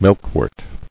[milk·wort]